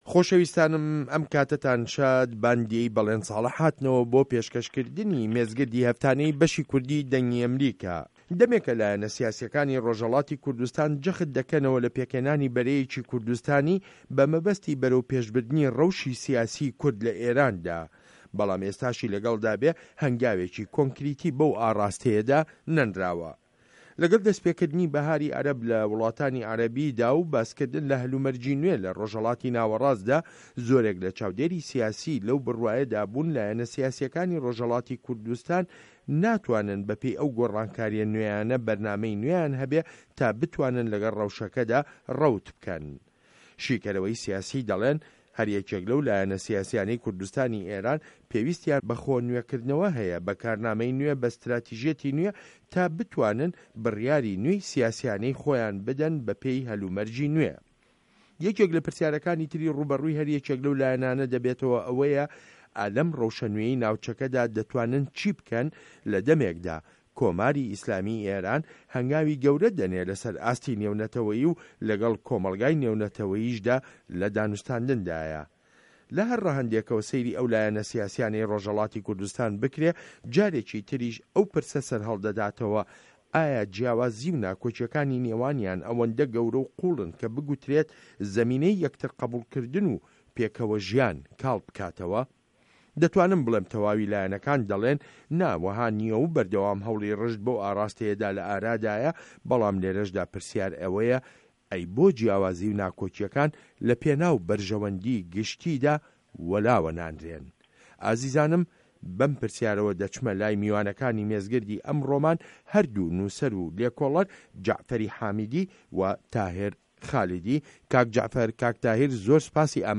مێزگرد: به‌ره‌یه‌کی کوردستانی بۆ لایه‌نه‌ سیاسیه‌کانی ڕۆژهه‌ڵاتی کوردستان